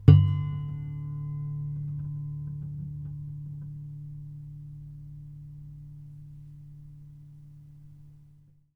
harmonic-12.wav